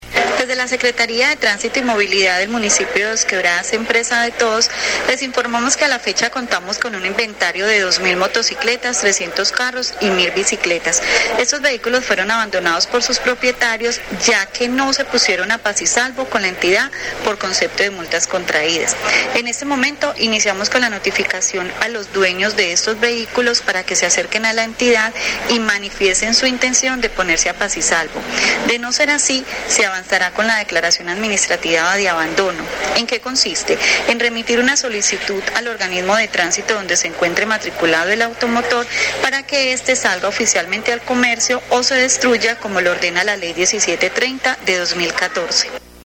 Comunicado-401-Audio-Secretaria-de-Tránsito-Ángela-Jazmín-Hidalgo.mp3